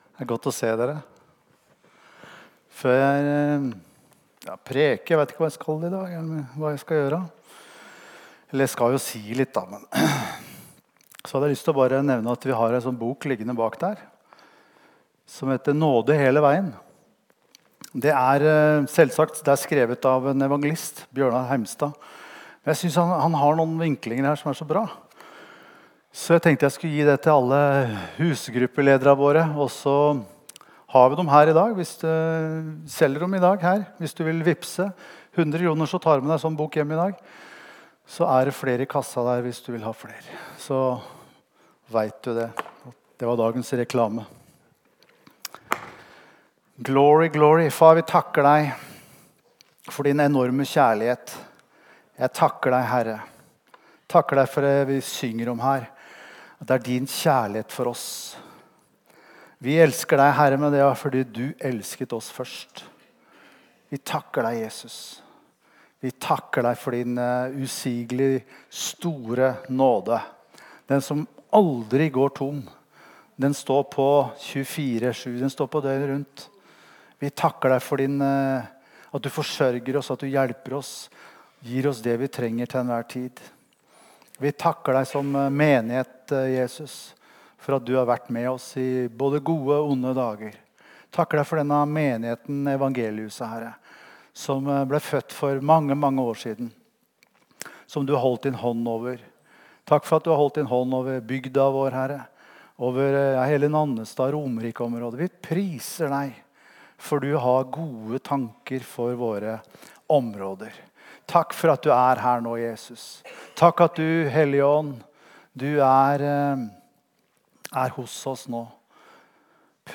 Gudstjenester